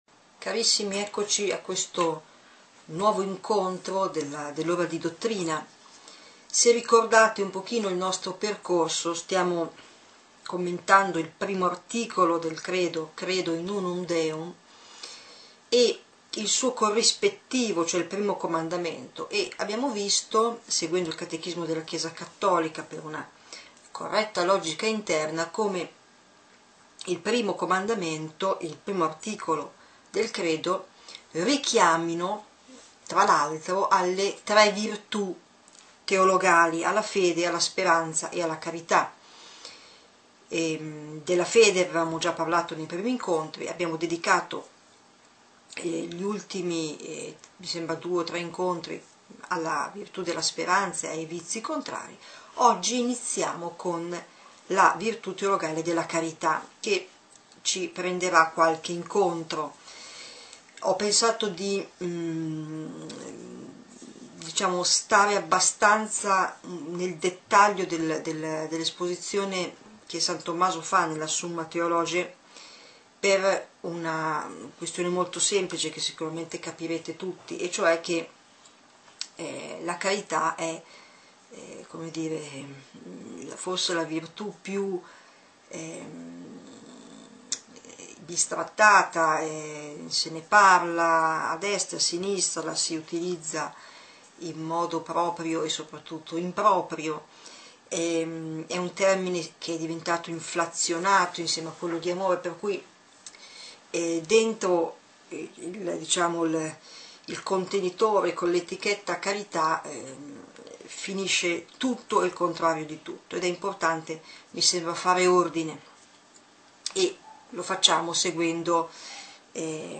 Catechesi adulti
CHE-COSE-LA-CARITA-Lezione-19-del-Catechismo.mp3